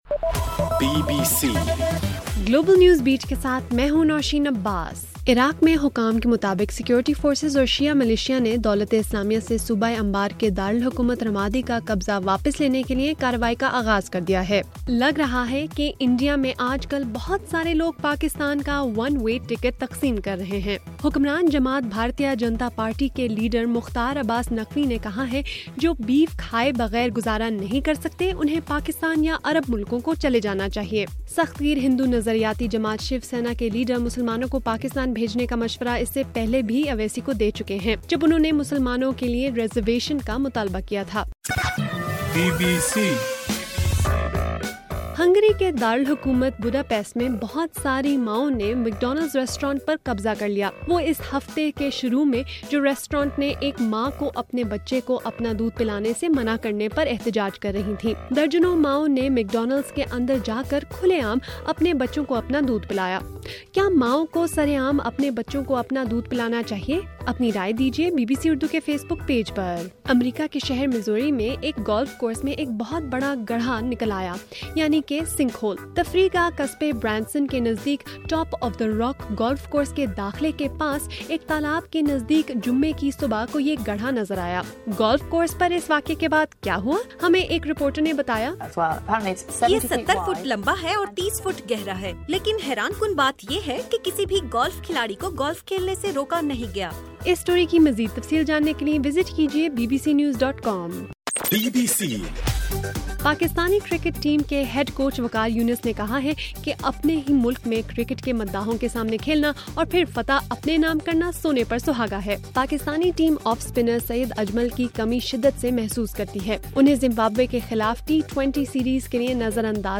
مئی 23: رات 8 بجے کا گلوبل نیوز بیٹ بُلیٹن